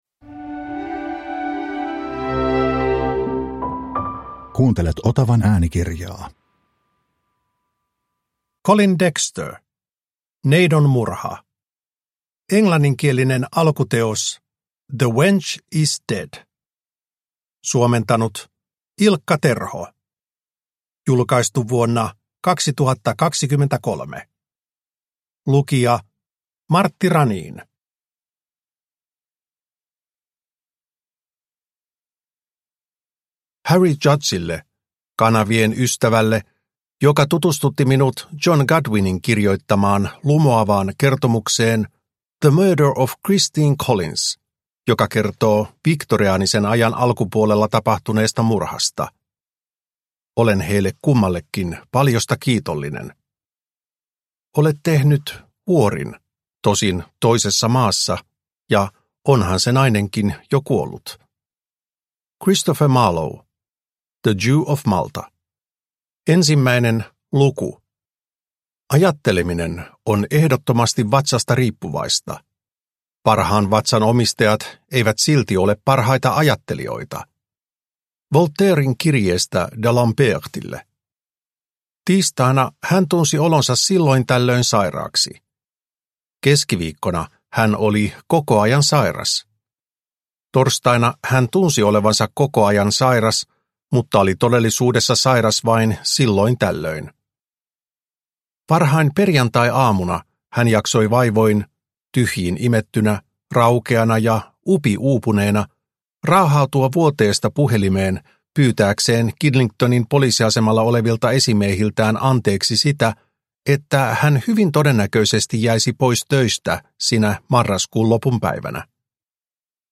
Neidonmurha – Ljudbok – Laddas ner